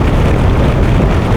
sci-fi_vehicle_spaceship_jet_engine_loop1.wav